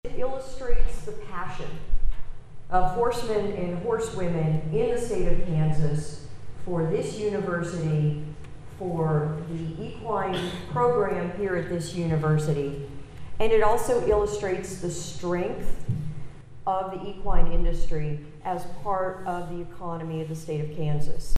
Friday’s ceremony was held inside the Stanley Stout Center, on the northern edge of the campus.